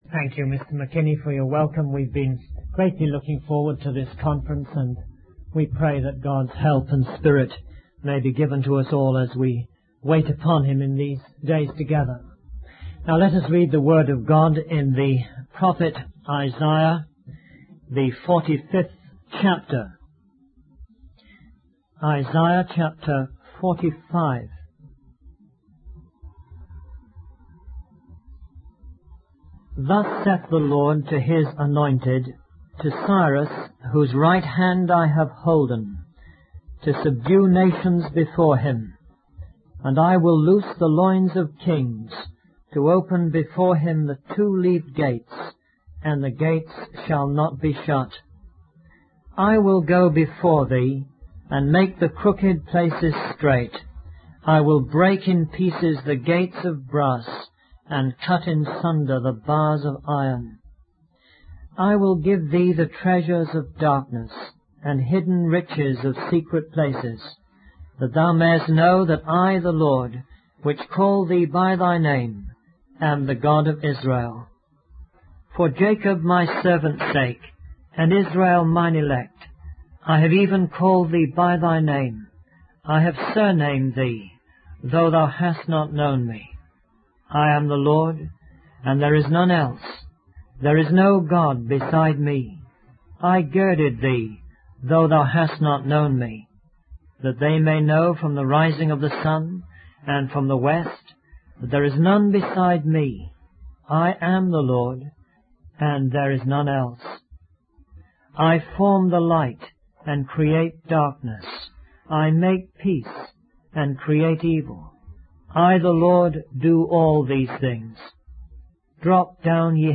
In this sermon, the preacher emphasizes the power of prayer and the importance of seeking God's will.